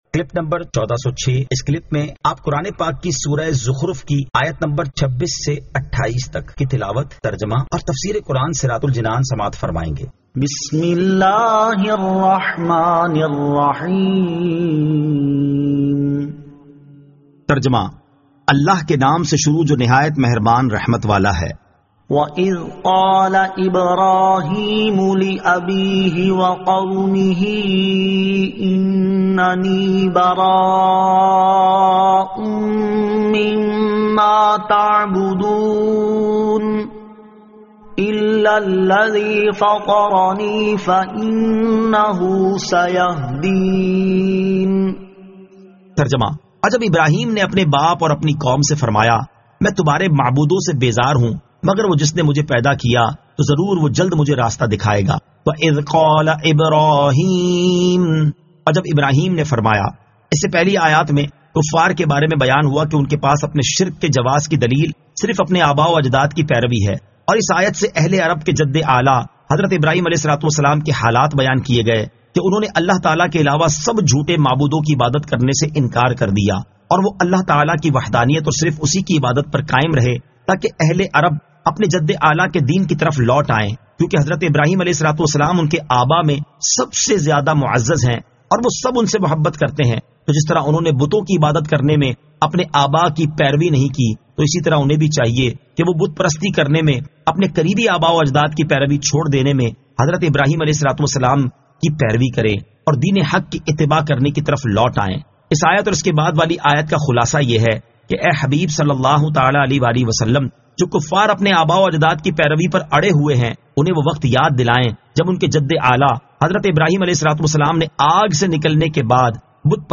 Surah Az-Zukhruf 26 To 28 Tilawat , Tarjama , Tafseer